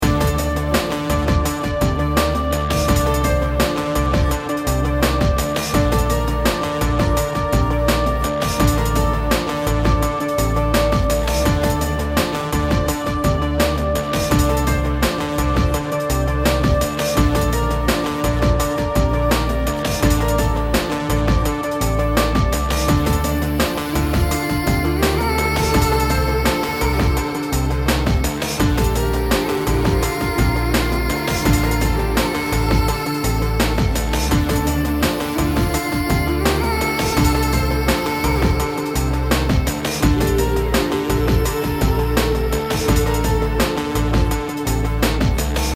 BPM 84